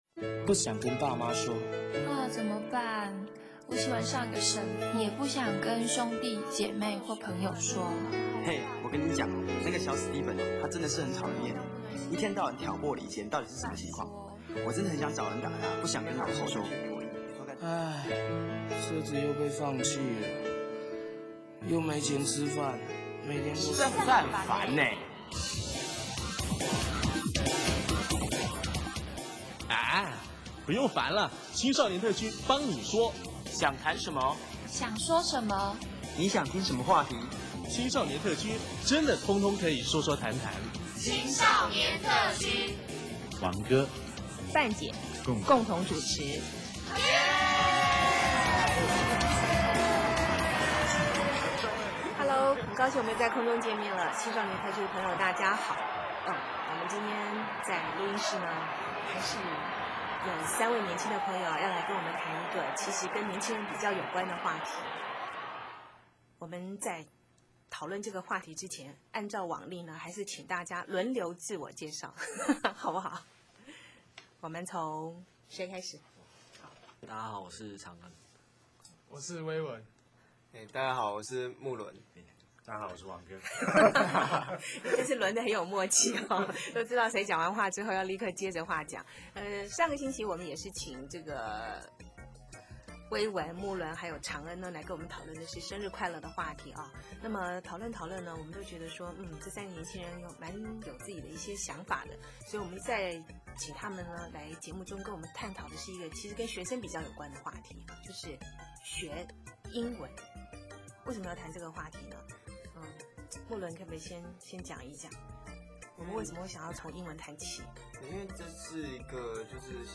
每个人都明白英文很重要，许多人也都经过苦学英文的阶段，但是大家的英文程度如何呢？听听三位青年学生学习英文的甘苦谈。